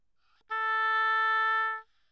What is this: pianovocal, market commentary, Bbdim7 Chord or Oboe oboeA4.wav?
Oboe oboeA4.wav